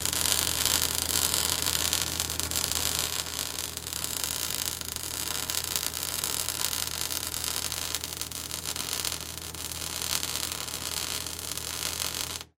Звуки сварочного аппарата
Промышленный сварочный пистолет для профессиональных работ